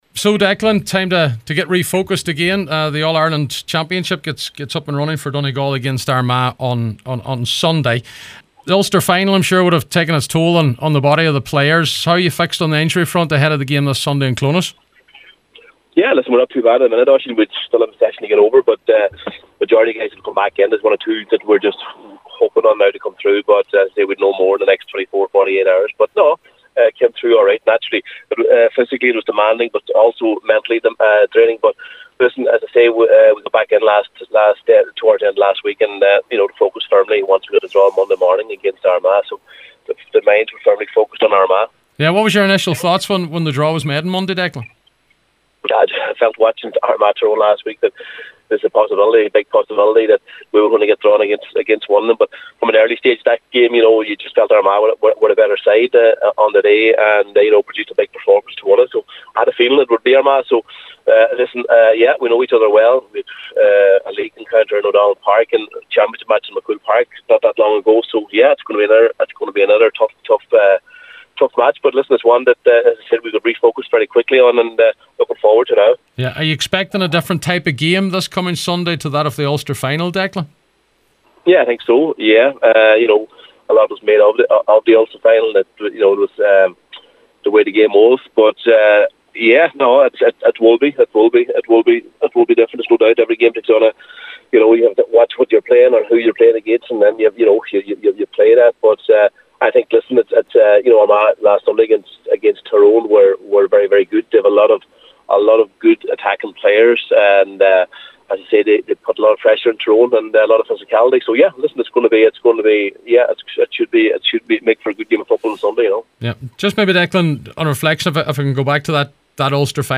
The Ulster campaign and in particular the Ulster Final was sore on the players but Manager Declan Bonner says they had responded well in preparing for the All Ireland series: